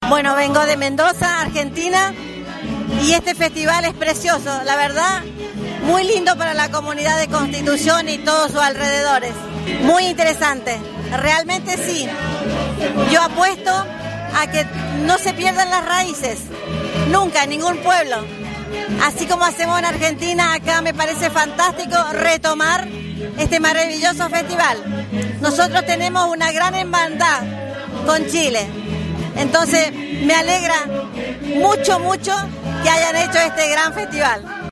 TURISTA ARGENTINA
TURISTA-ARGENTINA_01.mp3